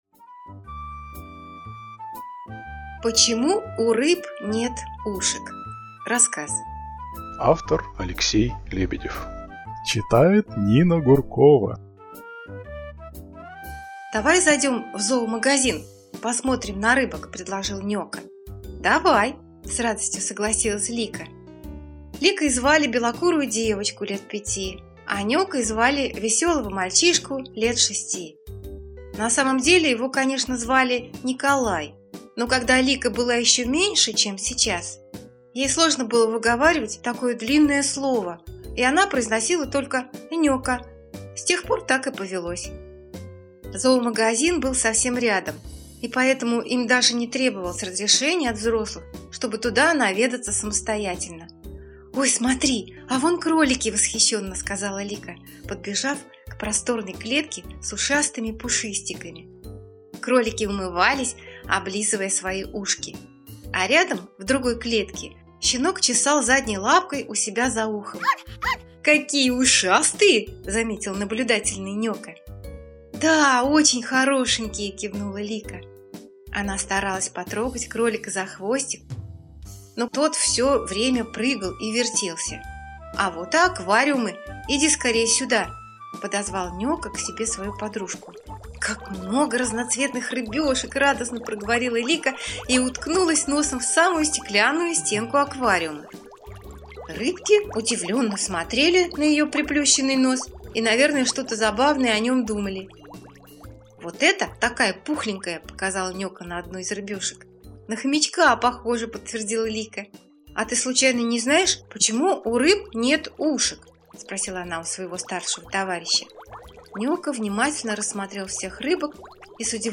Почему у рыб нет ушек - аудиосказка Лебедева - слушать